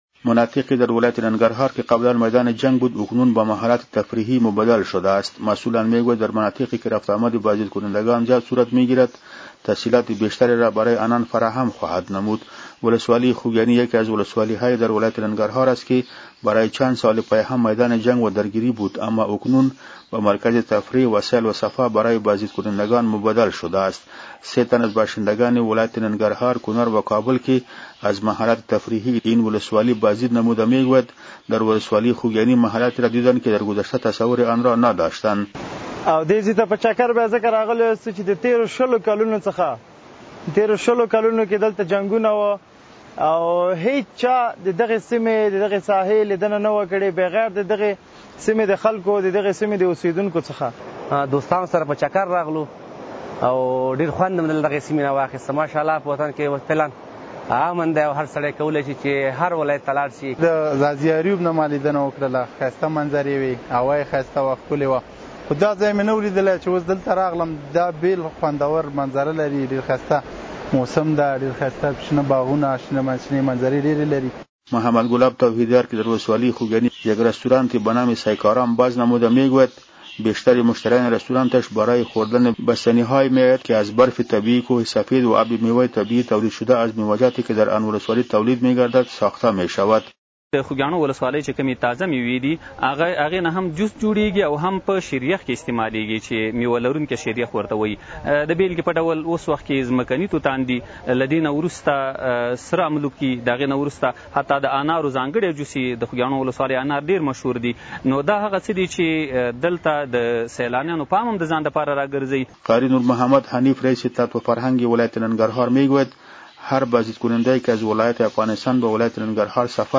خبر
سه تن از باشندگان ولایات ننگرهار، کنر و کابل که از محلات تفریحی این ولسوالی بازدید نموده، می گویند: در ولسوالی خوگیانی محلاتی را دیدند که در گذشته تصور آنرا نداشتند.